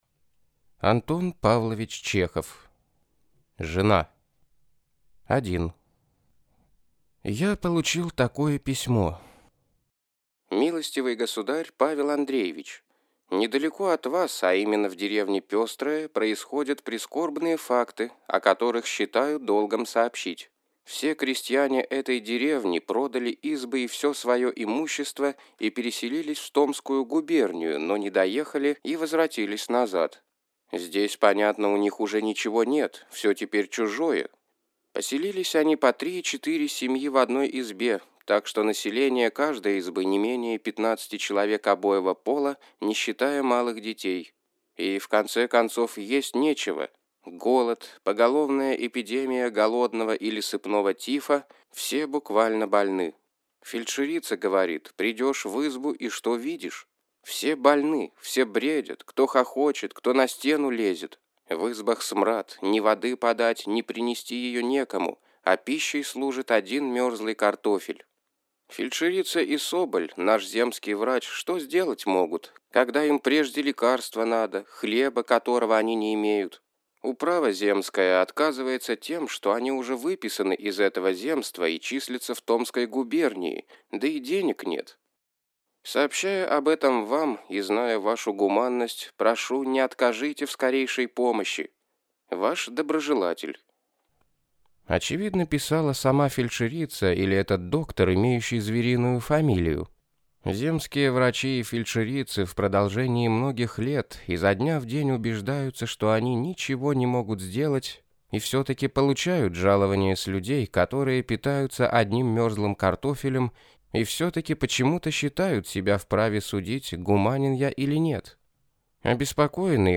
Аудиокнига Жена | Библиотека аудиокниг